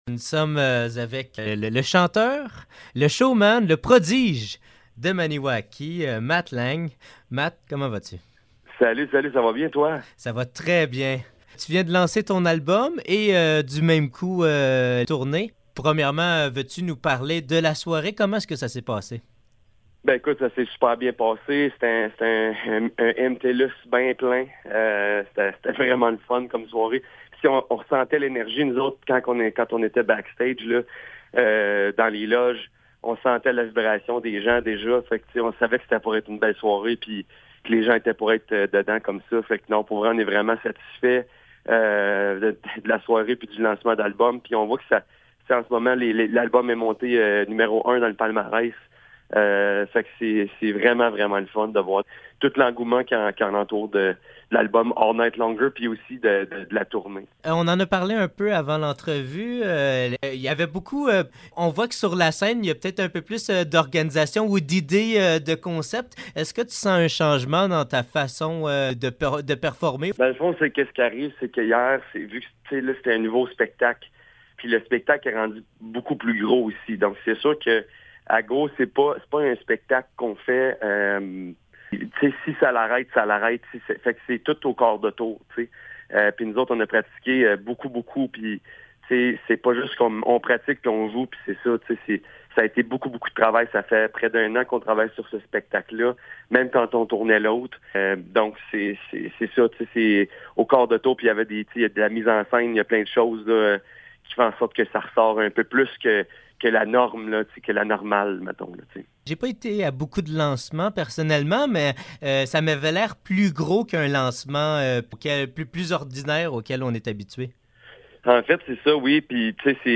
Entrevue avec Matt Lang